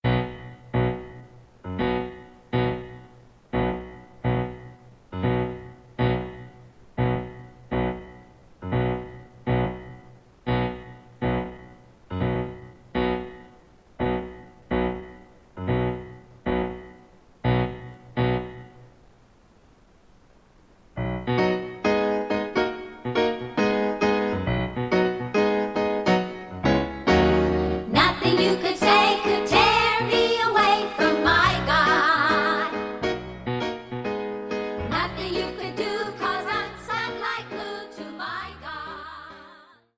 This is an instrumental backing track cover.
• With Backing Vocals
• No Fade